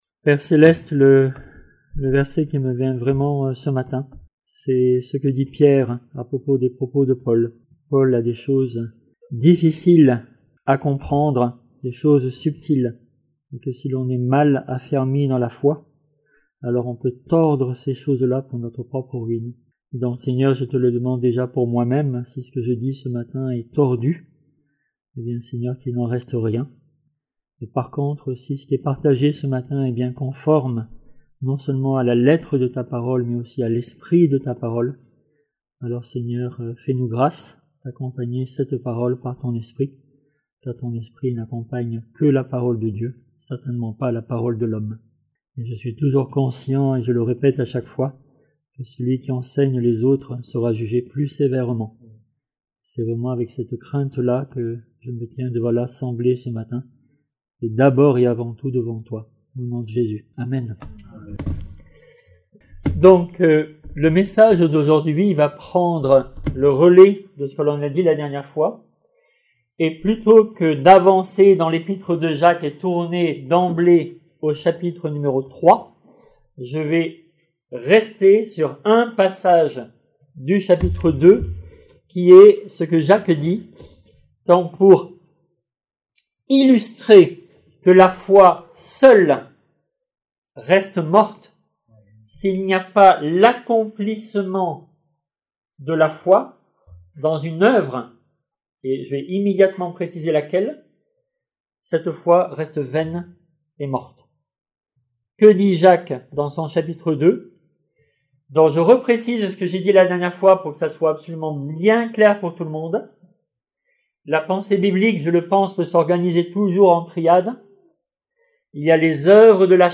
Prédicateurs